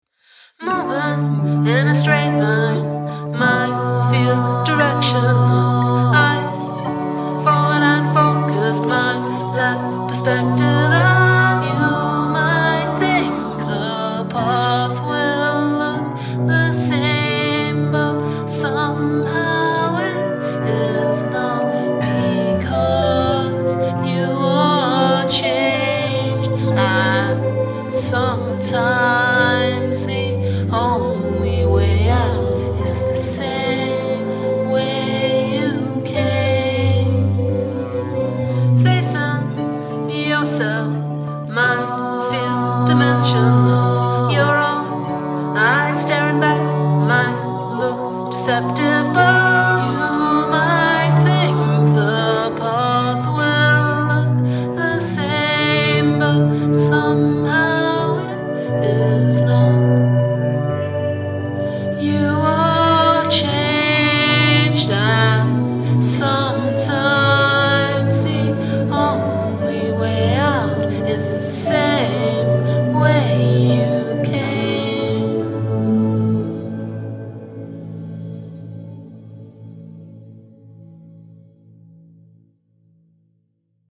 Downward Modulation
Particularly the chorused flute, on closer inspection!